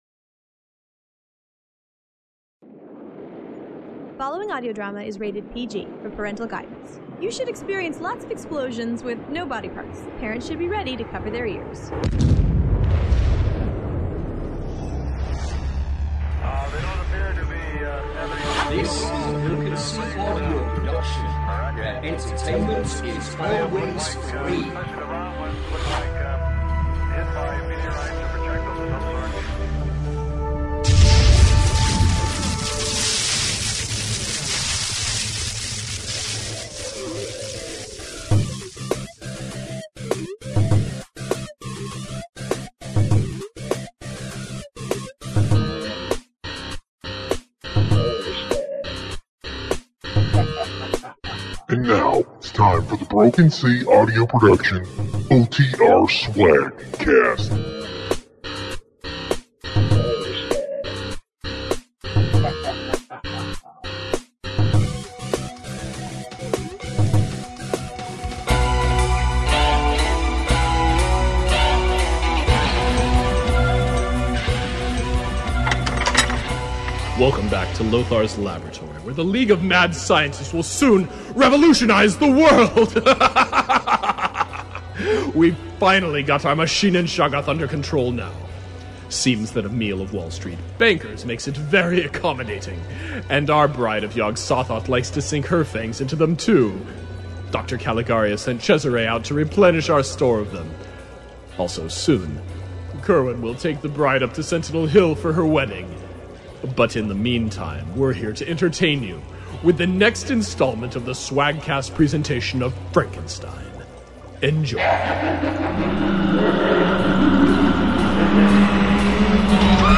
An original Science Fiction Audio Drama….